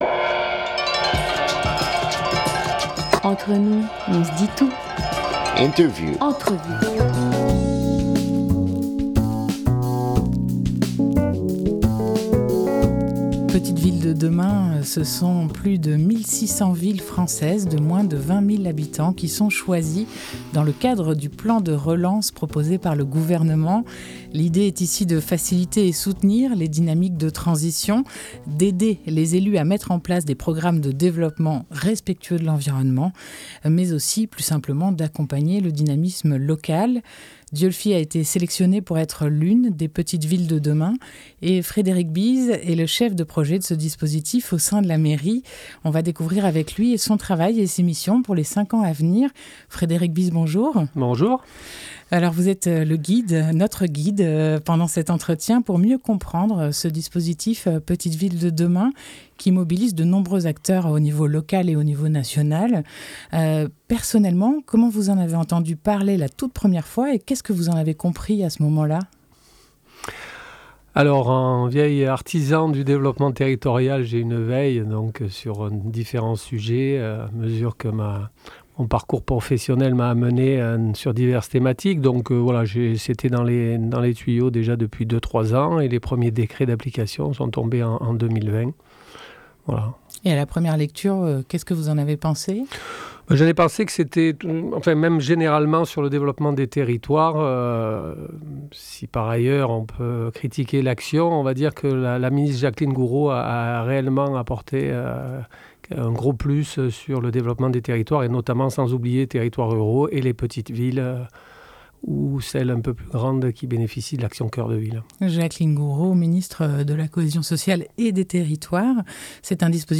29 novembre 2021 10:00 | Interview